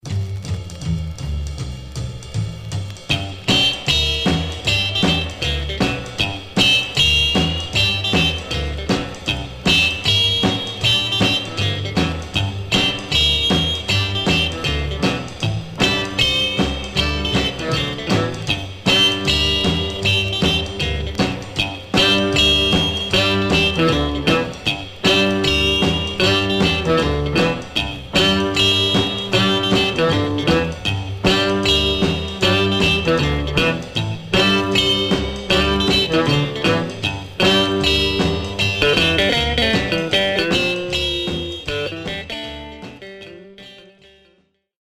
Stereo/mono Mono
R & R Instrumental Condition